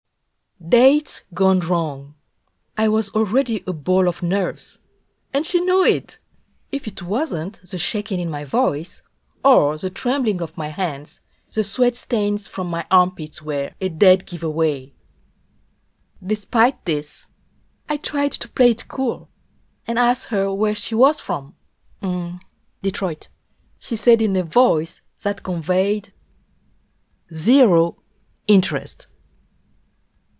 Audio Book Samples
Fiction (English)
Comedy, Lively, Sparkling